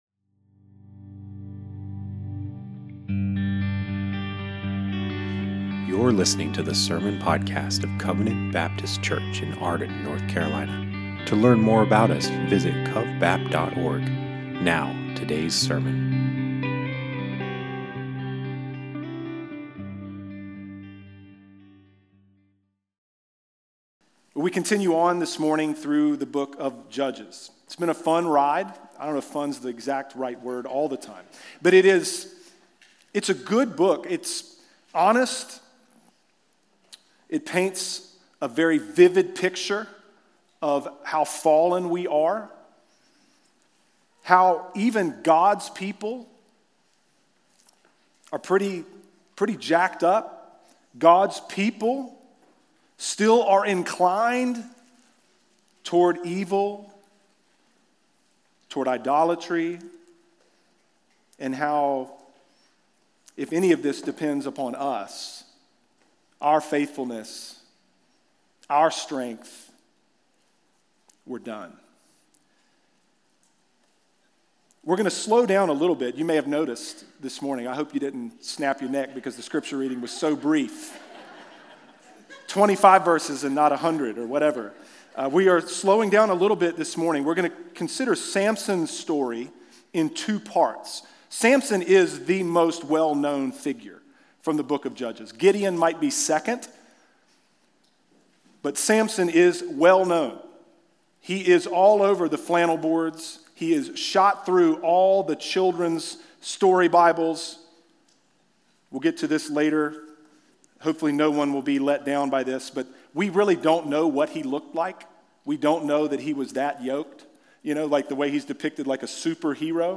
Covenant Baptist Church Sermons